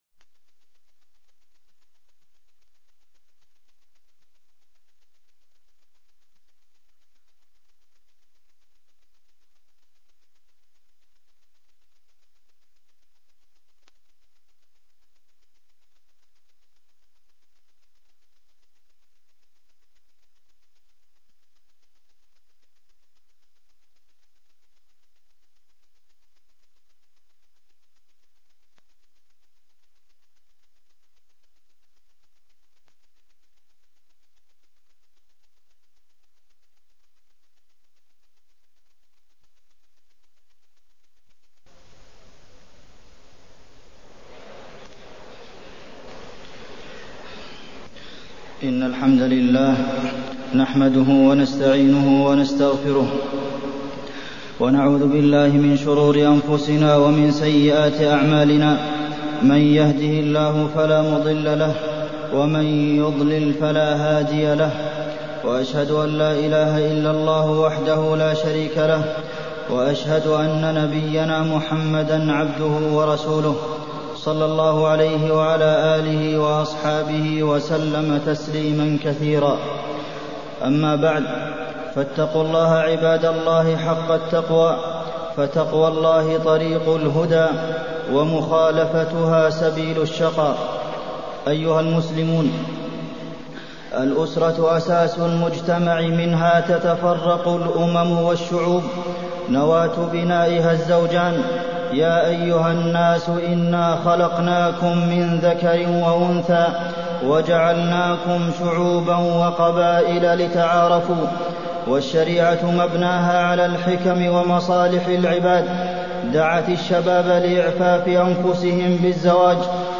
تاريخ النشر ٤ جمادى الأولى ١٤٢٤ هـ المكان: المسجد النبوي الشيخ: فضيلة الشيخ د. عبدالمحسن بن محمد القاسم فضيلة الشيخ د. عبدالمحسن بن محمد القاسم الزواج